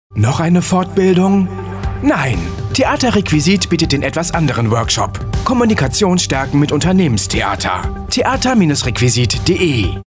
Unser neuer Radio-Spot auf HIT RADIO FFH
Theater RequiSiT ist mit einem brandneuen Radio-Spot auf HIT RADIO FFH zu hören – im beliebten Format der ExtraTipps.
Theater-RequiSiT_Radiospot_FFH.mp3